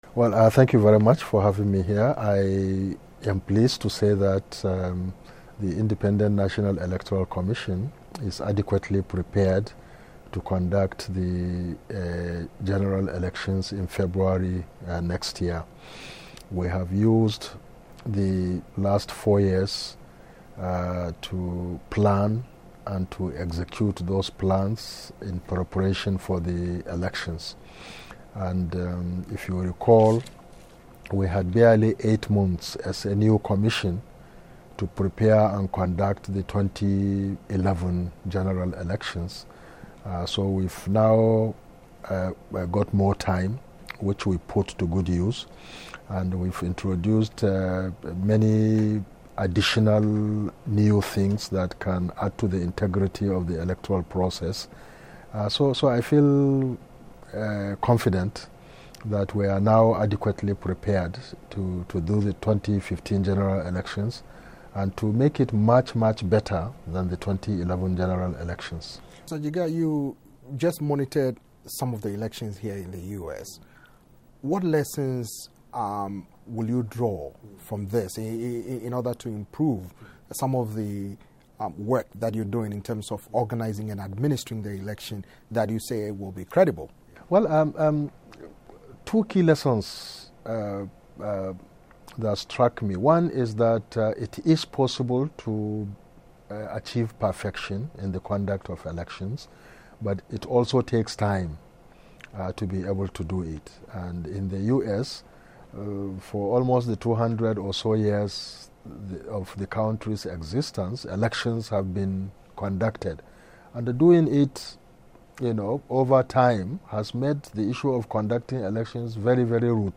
Voice of America (VOA) interview with Professor Attahiru Jega, Chairman Nigeria Electoral Commission
voa-interview-with-professor-attahiru-jega-chairman-nigeria-electoral-commission.mp3